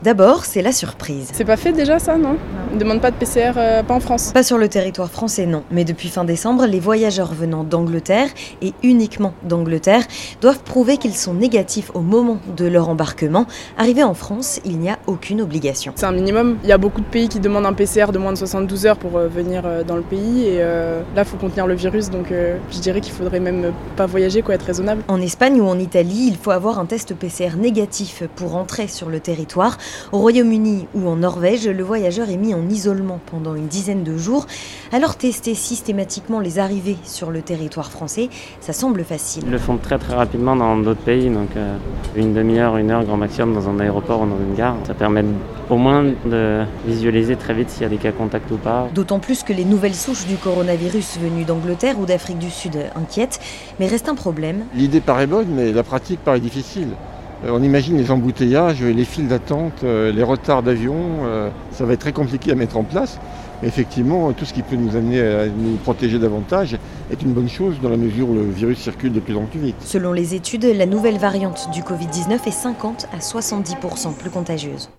La gare de Lyon à Paris accueille des passagers venus d’Italie ou de Suisse. Que pensent les voyageurs d’une telle proposition ?
Reportage